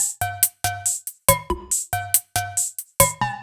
Index of /musicradar/french-house-chillout-samples/140bpm/Beats
FHC_BeatD_140-03_Tops.wav